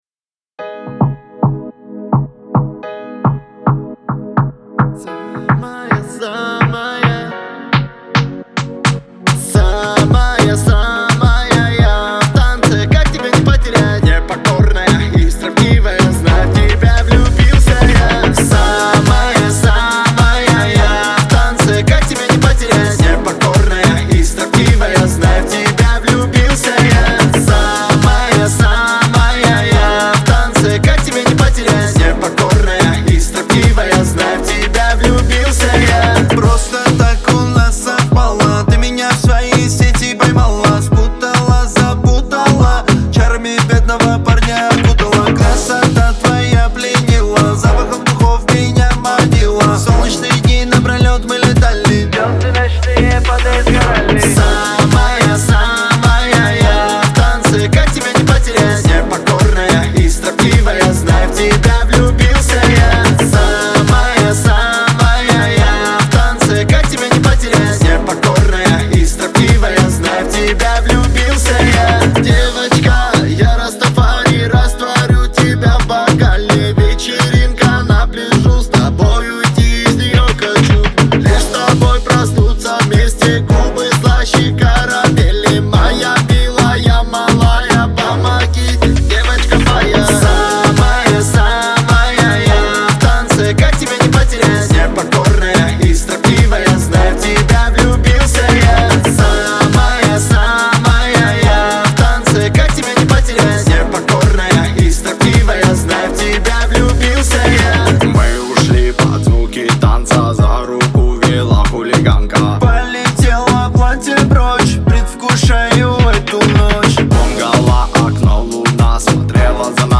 это энергичная композиция в жанре регги и хип-хоп